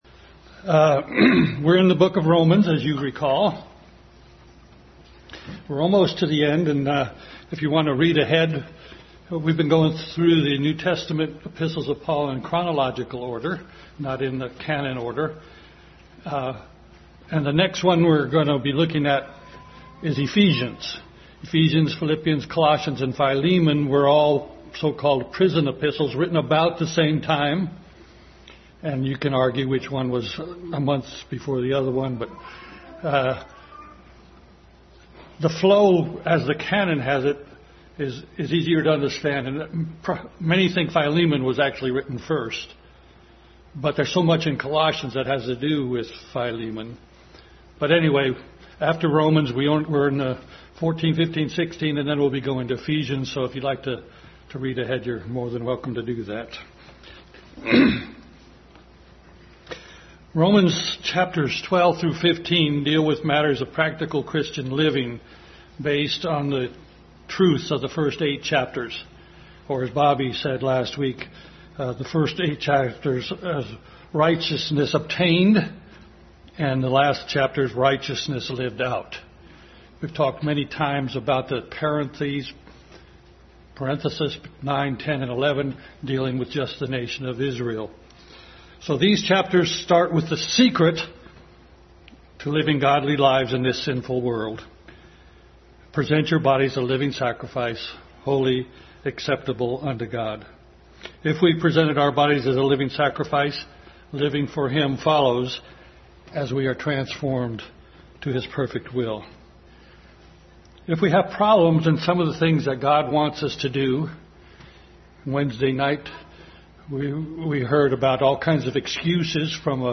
Adult Sunday School Class continued study in Romans.
Romans 13:8-14 Service Type: Sunday School Adult Sunday School Class continued study in Romans.